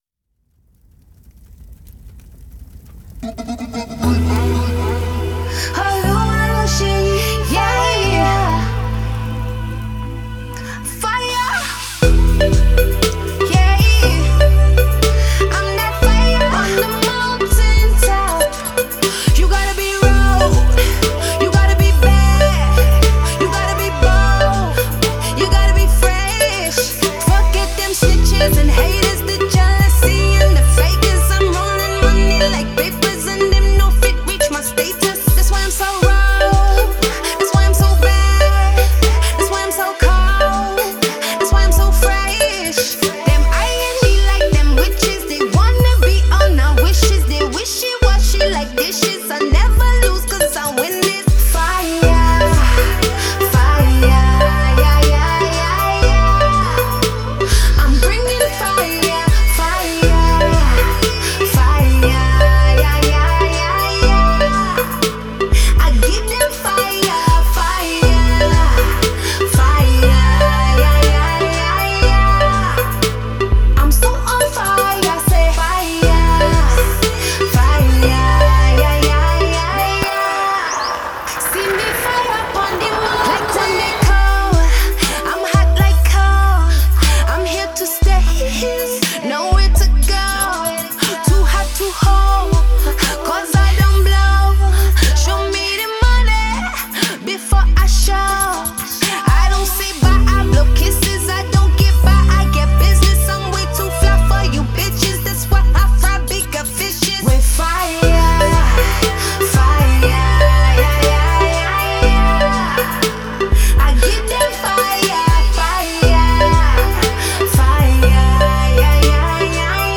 sultry new track